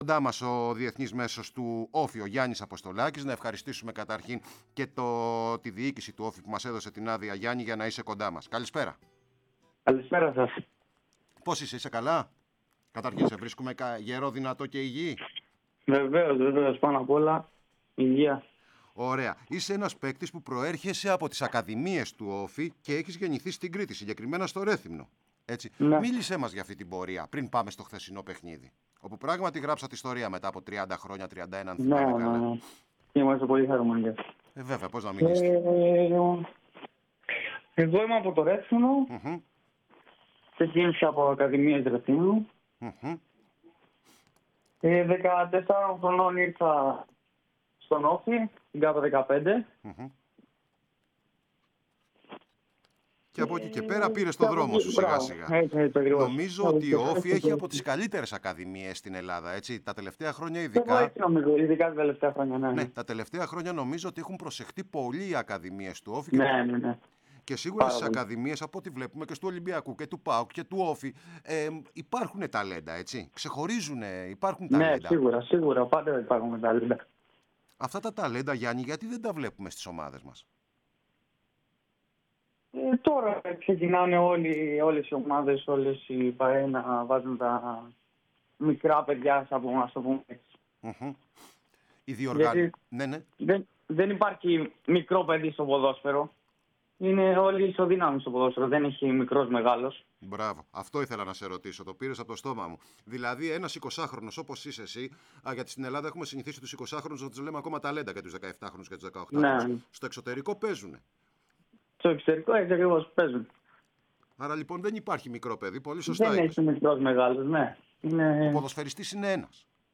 μίλησε στην κορυφαία ραδιοφωνική συχνότητα της χώρας